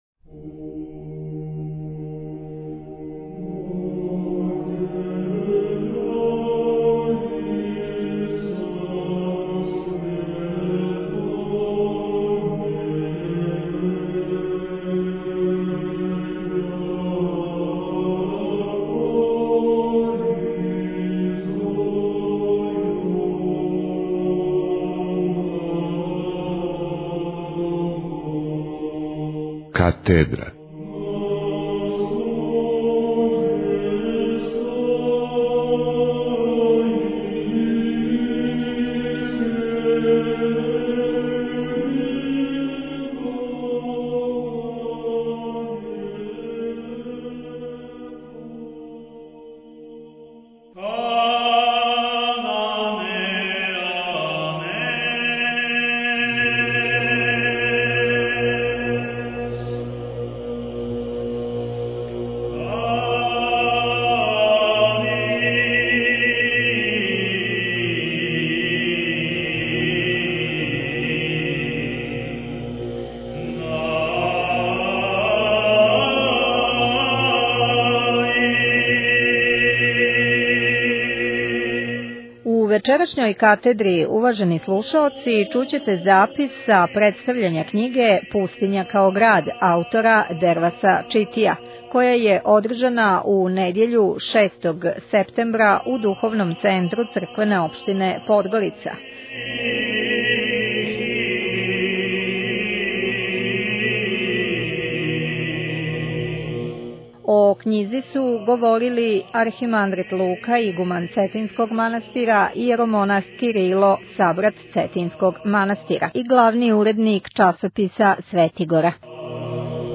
Наслов: Pustinja kao grad Албум: Katedra Година: 2009 Величина: 67:10 минута (9.62 МБ) Формат: MP3 Mono 11kHz 20Kbps (VBR) У недјељу 6. септембра 2009. г. у Духовном центру у Подгорици представљена је књига «Пустиња као град» аутора Дерваса Читија.